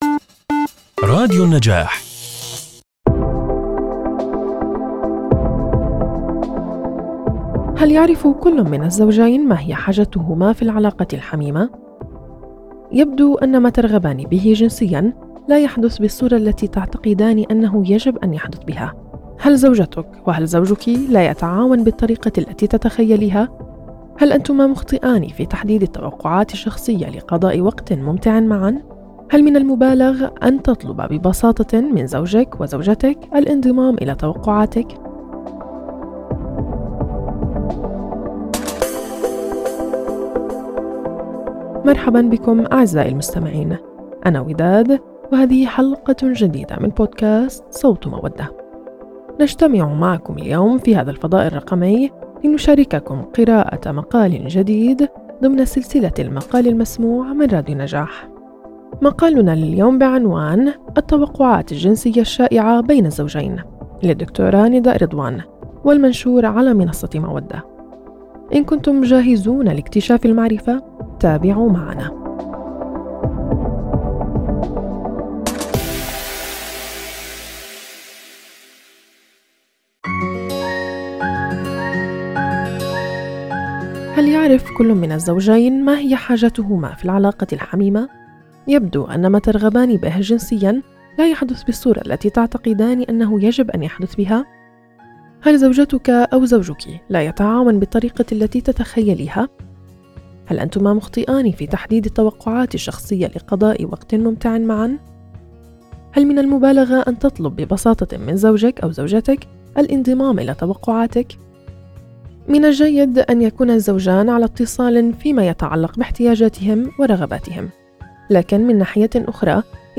“صوت مودة” هو بودكاست فريد من نوعه ضمن سلسلة “المقال المسموع” التي يقدمها راديو النجاح. في هذا البودكاست، ننقل لكم المقالات المتنوعة والغنية المنشورة على منصة مودة إلى عالم الصوت، مما يوفر تجربة استماع ممتعة وملهمة.